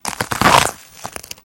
Звуки арбуза